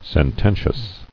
[sen·ten·tious]